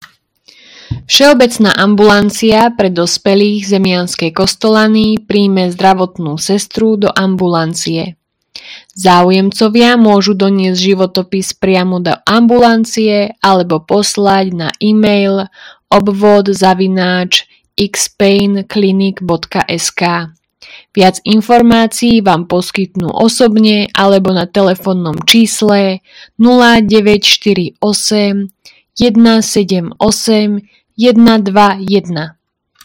Hlásenie obecného rozhlasu – Ponuka práce – Zdravotná sestra v Zemianskych Kostoľanoch